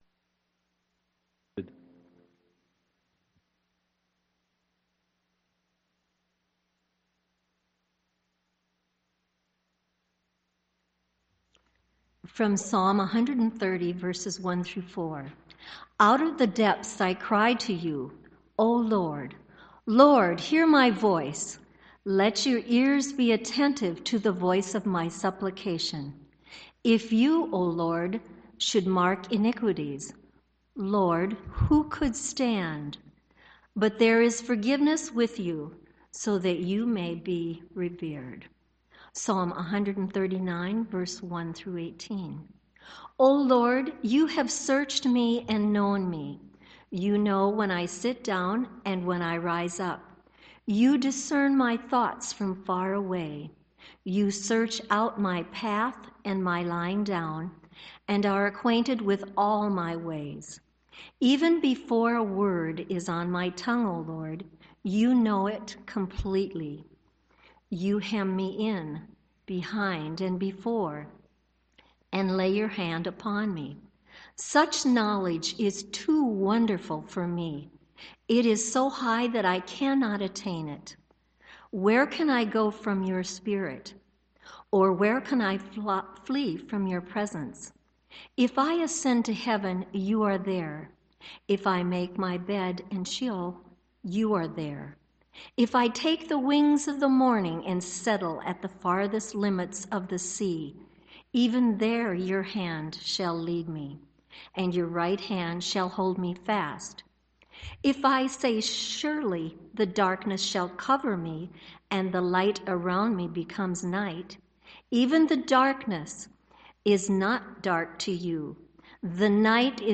Sermon-2015-12-19-Blue-Christmas.mp3